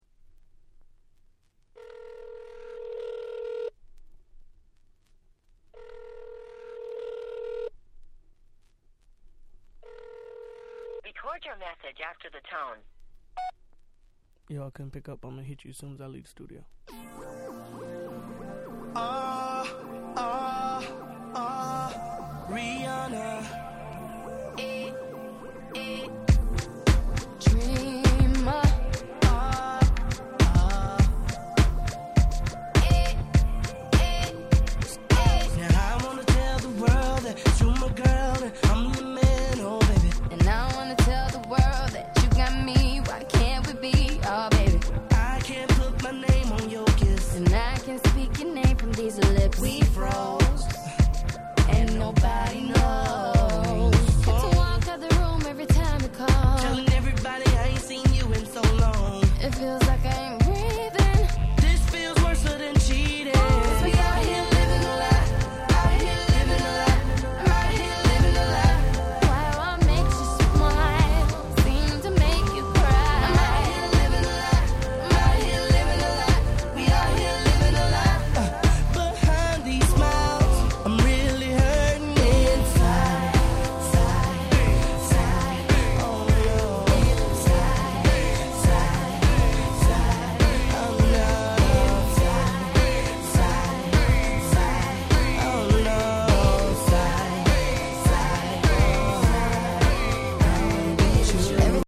07' Nice R&B !!